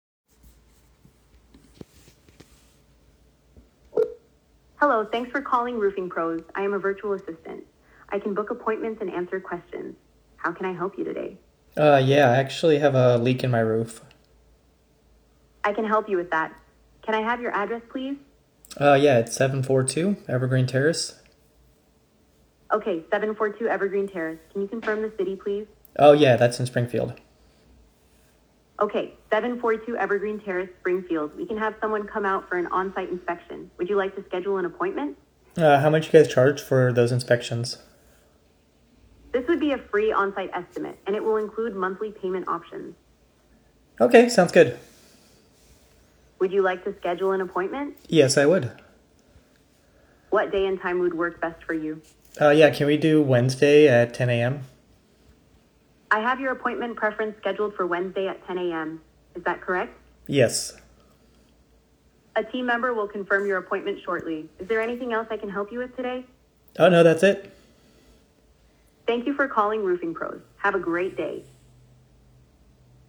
Your 24/7 AI Receptionist